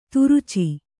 ♪ turuci